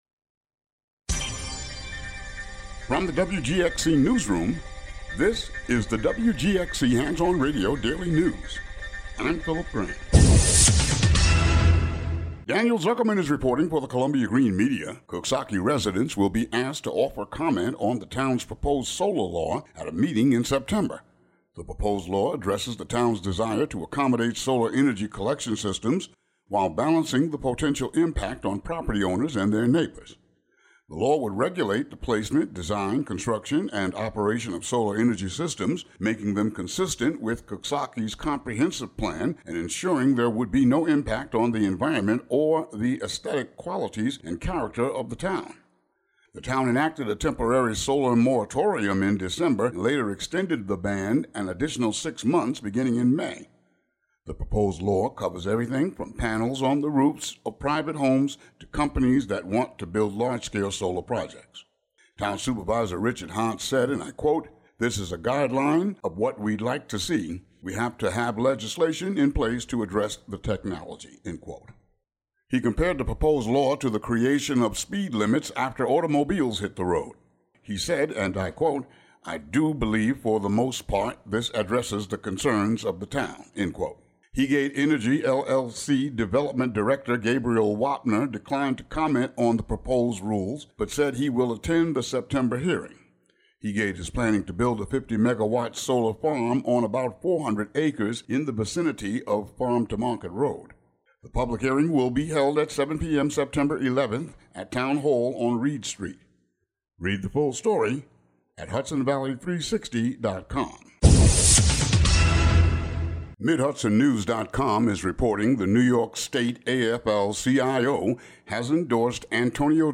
Daily headlines for WGXC.